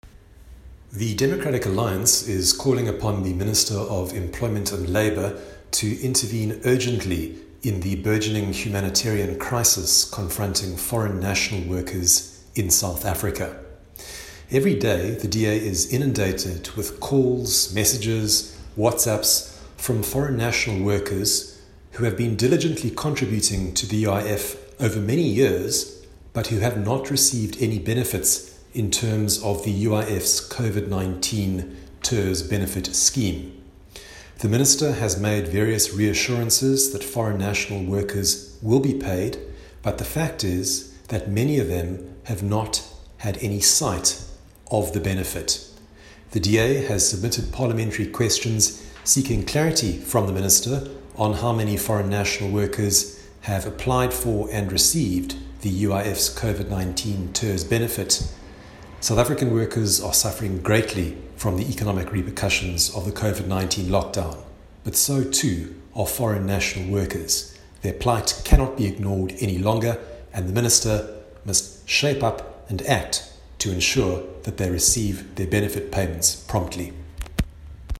soundbite from Dr Michael Cardo, DA Shadow Minister for Employment and Labour.